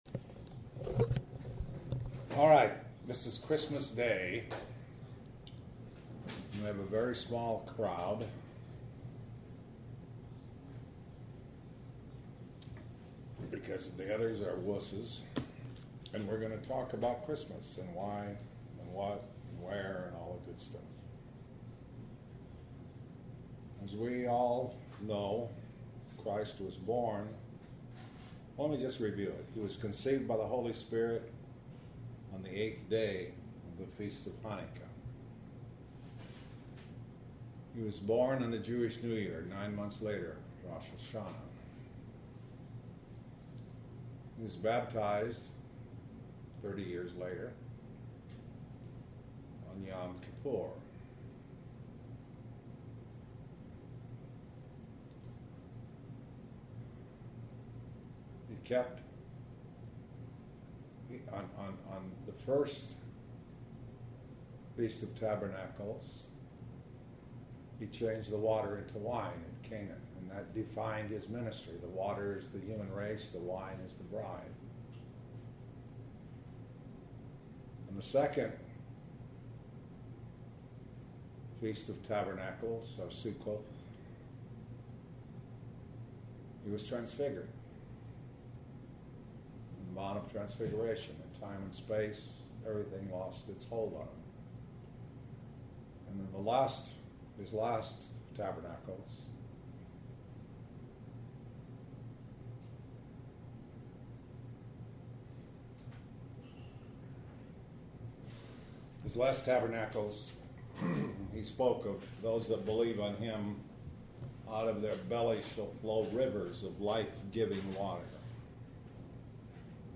Christ actually born around Rosh Hashanah. A discussion of the feasts.